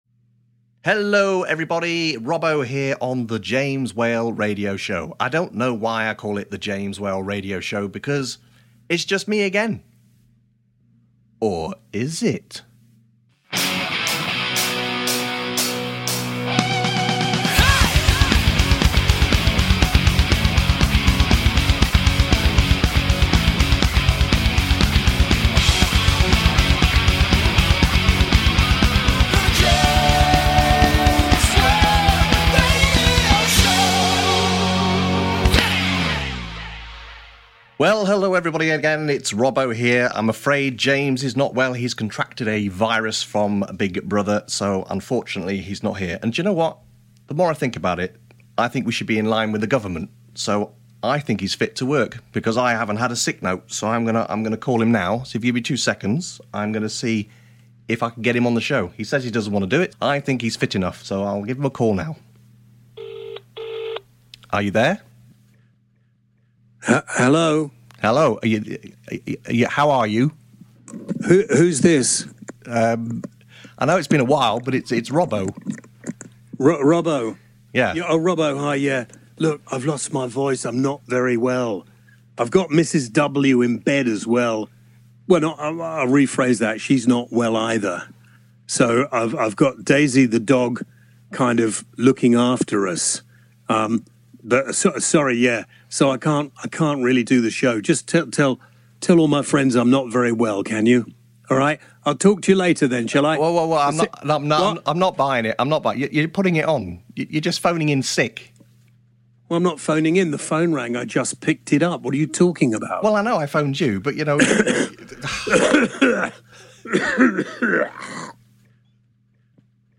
The Big Brother Interview – James Whale Radio Show